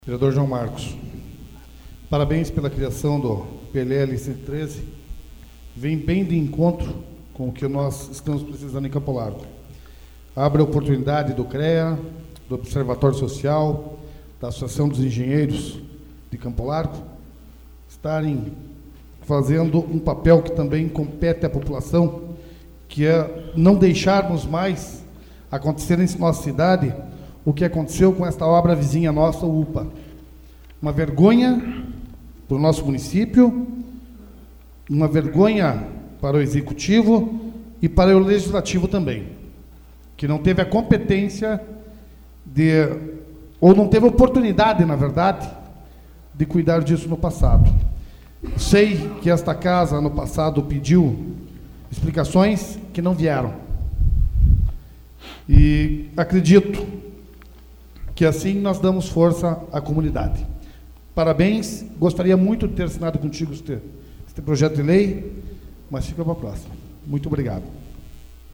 Discussão AVULSO 26/11/2013 Junior Torres